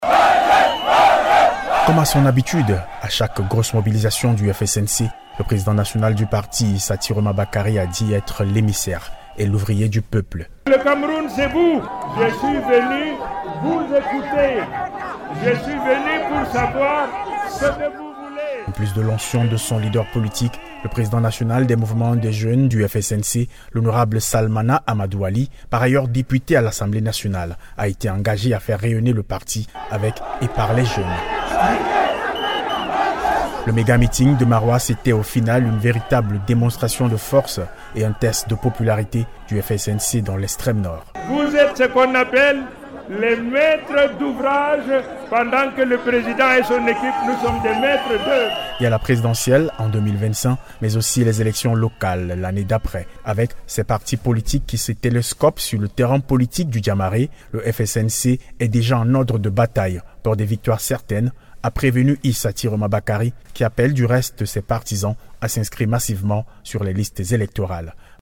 le reportage en question